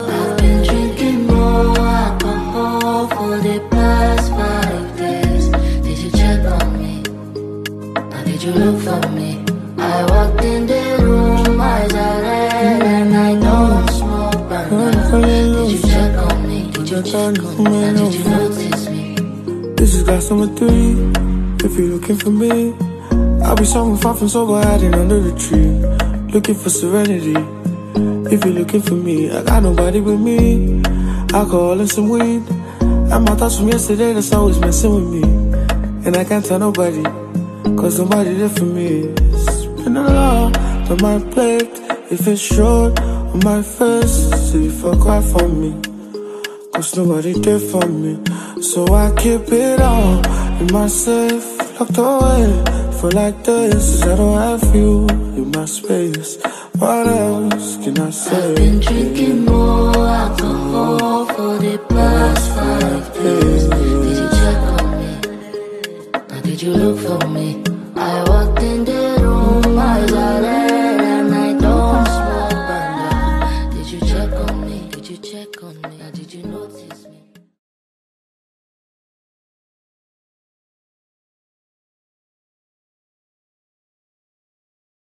catchy vocals